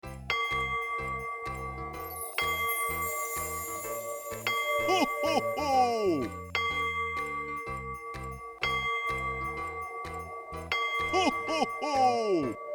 cuckoo-clock-06.wav